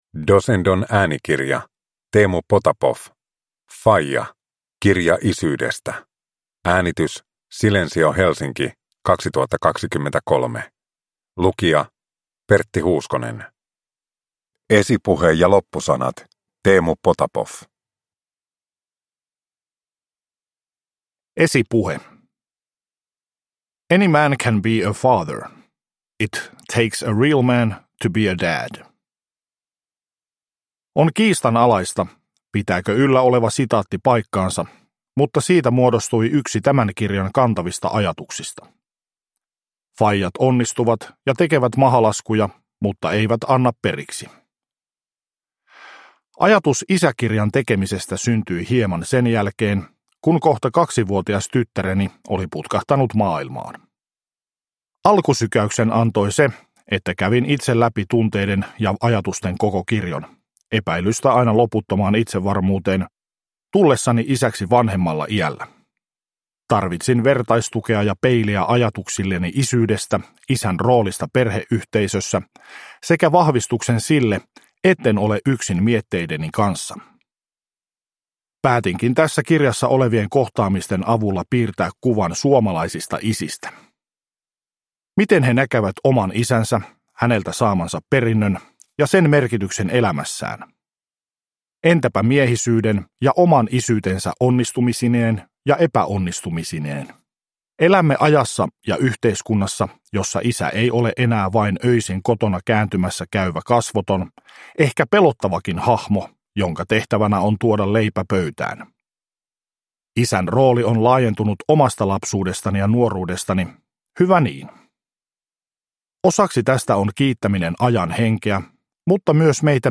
Faija – Kirja isyydestä – Ljudbok – Laddas ner